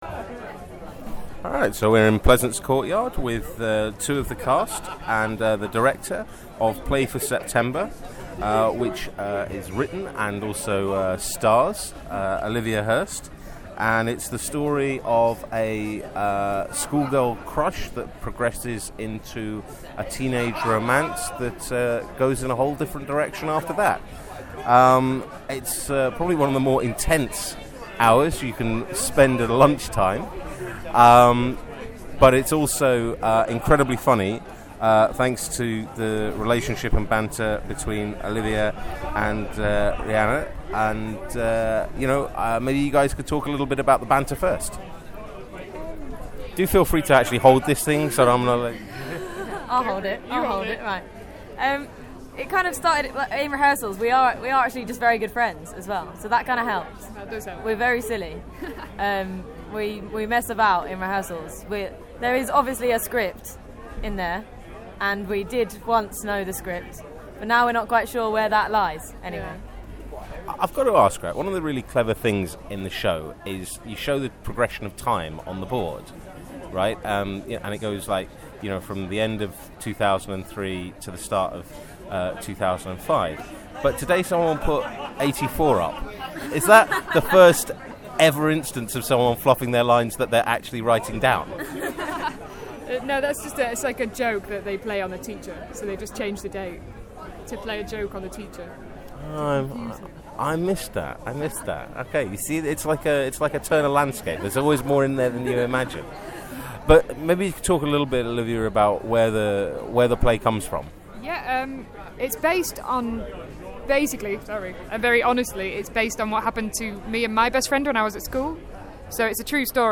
Interview with A Play For September
listen-to-our-interview-with-a-play-for-september.mp3